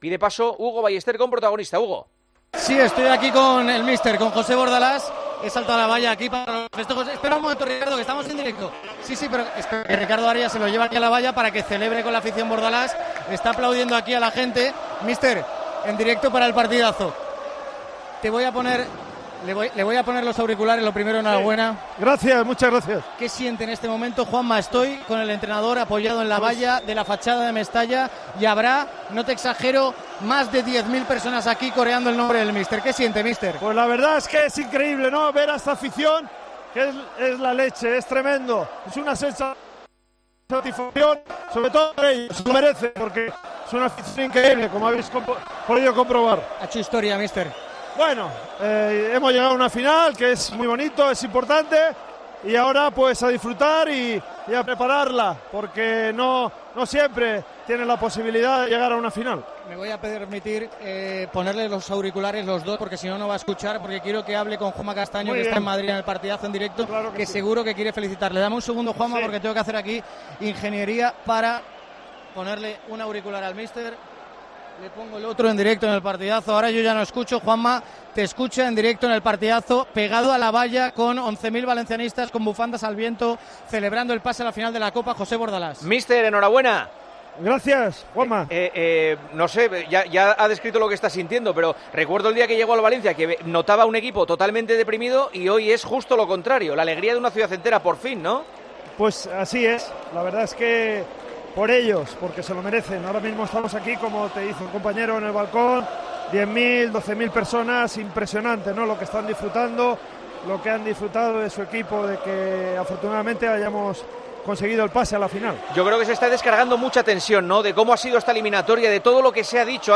El Valencia ha conseguido este miércoles clasificarse para la final de la Copa del Rey tras ganar al Athletic de Bilbao con un solitario gol de Guedes. En plena celebración, el técnico del conjunto 'che', José Bordalás, ha atendido a El Partidazo de COPE desde Mestalla.
Un triunfo que el alicantino ha querido dedicar a la afición valencianista que, en plena entrevista, estaban celebrando en las puertas de Mestalla el pase a la final.